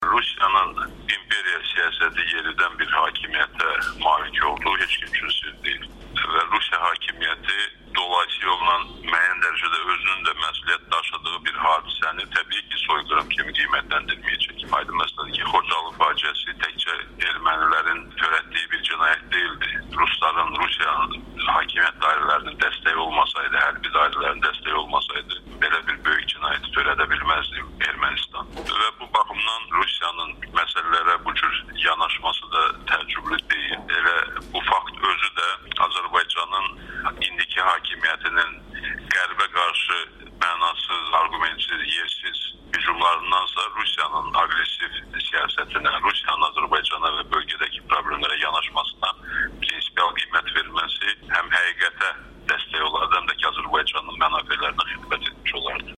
Rusiyanın Srebrenitsa faciəsini qəbul etməməsi Azərbaycanda etirazla qarşılanıb [Audio-müsahibələr]